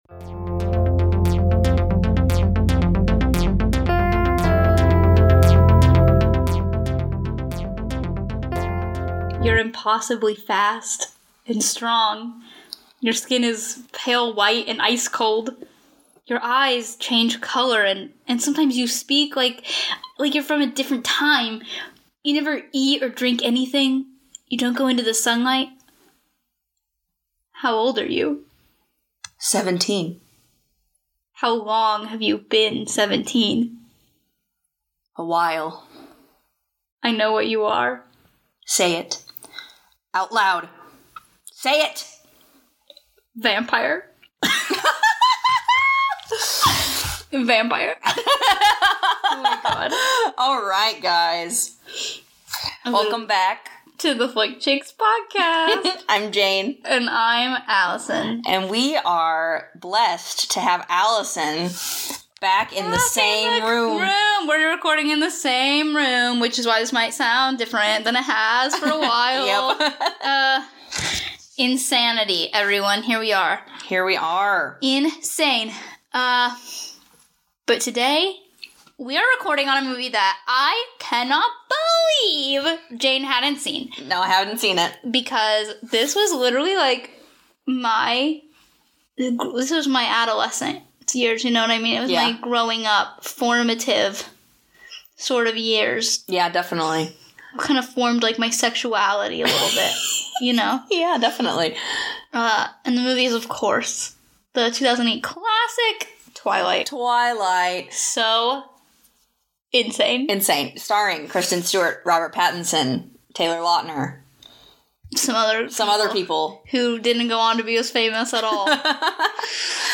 Please enjoy the cleaned up audio and more related surprises coming!